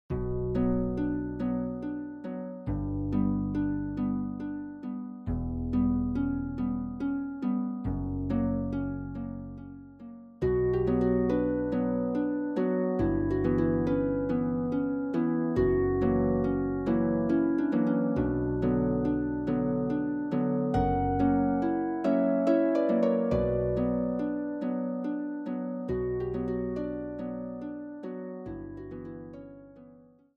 for solo pedal harp